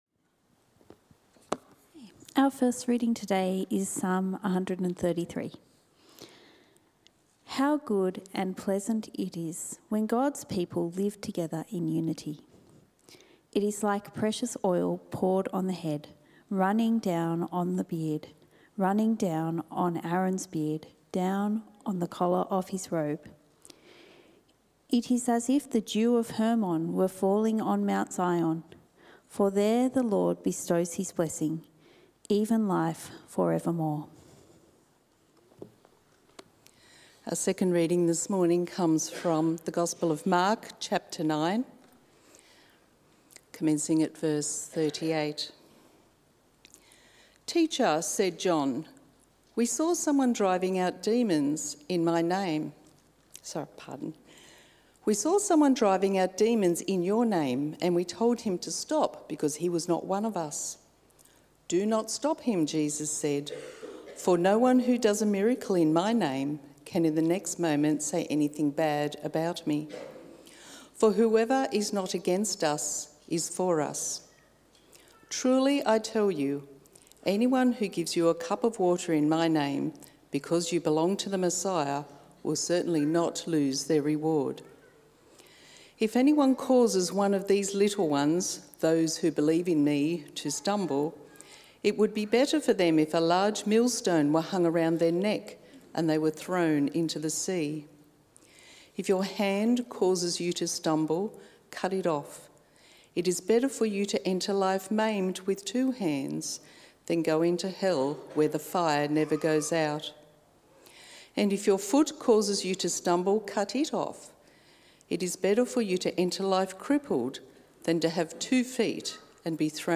A message from the series "An Invitation to Church."